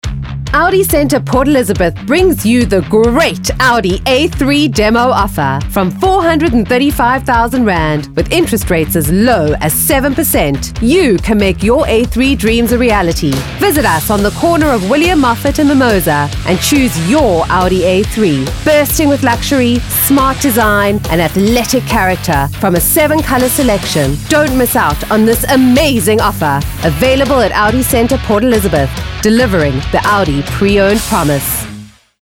South Africa
clear, comforting, gentle, inviting, precise, warm
45 - Above
My demo reels